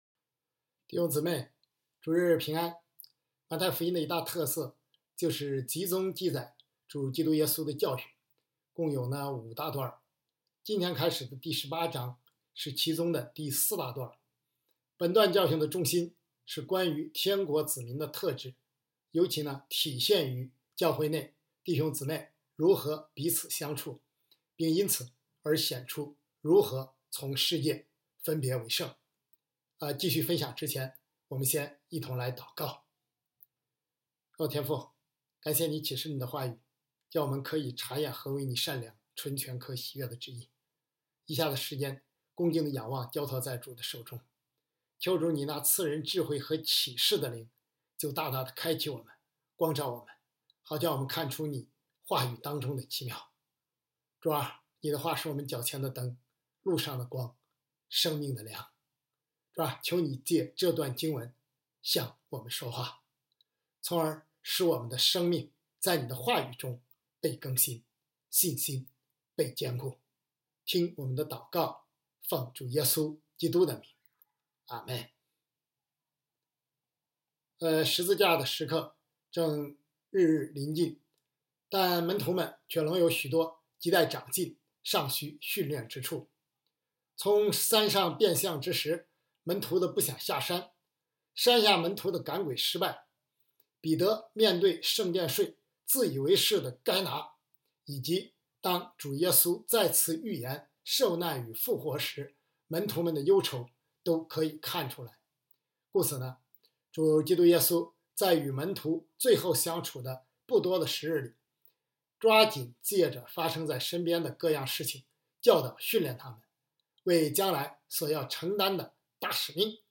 回转变成小孩子的样式——2026年1月18日主日讲章